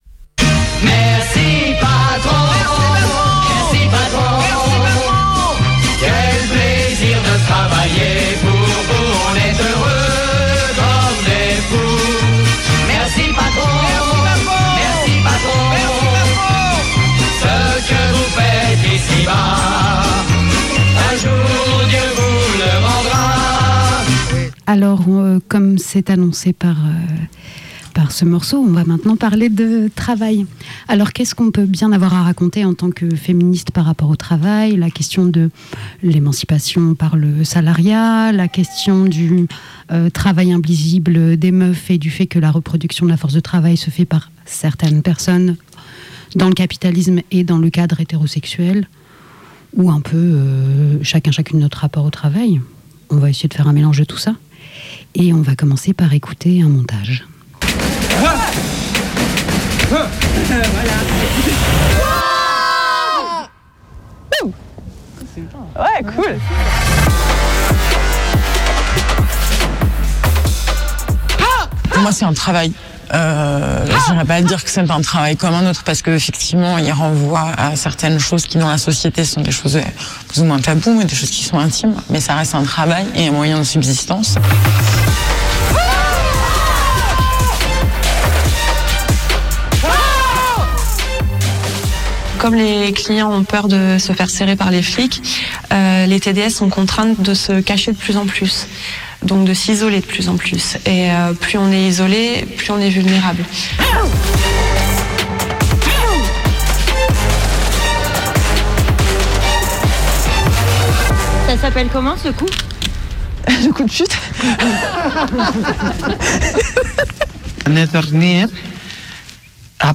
Burn out or not ? 38min34s Une table ronde pour causer de notre rapport au travail et au salariat...